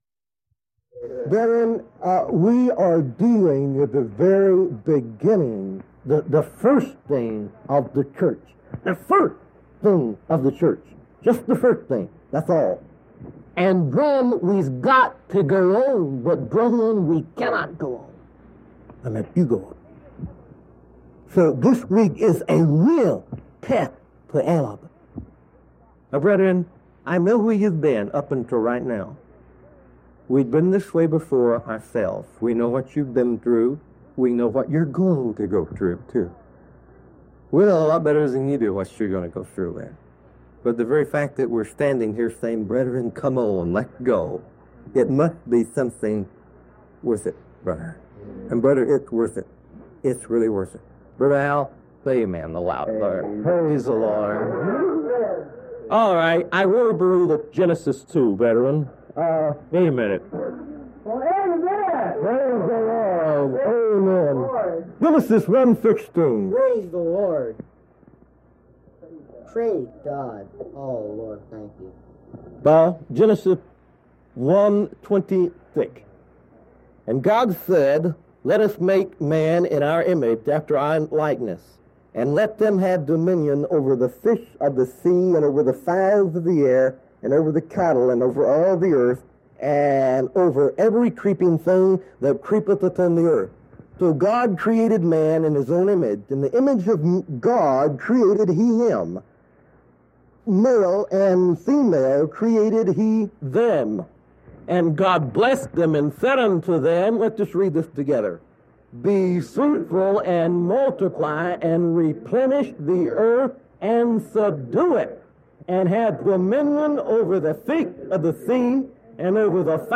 A deep biblical teaching on God’s eternal purpose, the corporate new man, and the church built together from Genesis to Revelation.